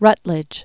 (rŭtlĭj), John 1739-1800.